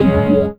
17CHORD03 -R.wav